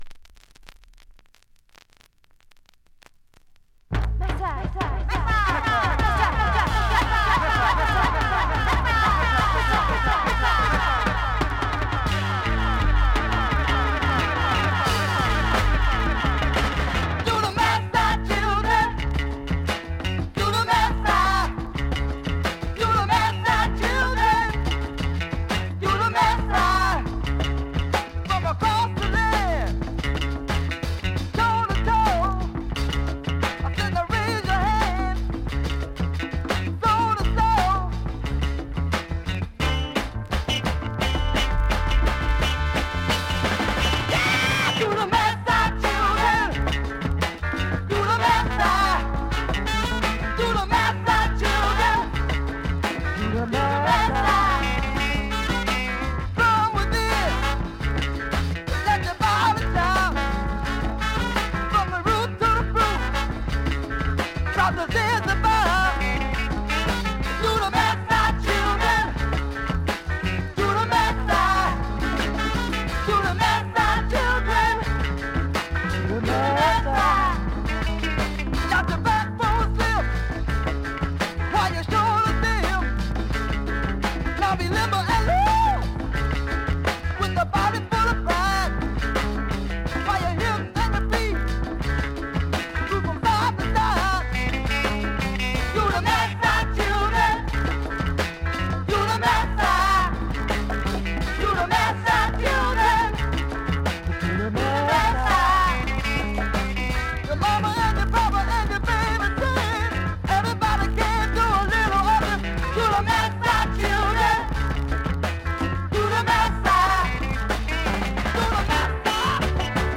現物の試聴（両面すべて録音時間５分３６秒）できます。 イントロのエコー処理からして ぶっ飛びのナイスファンク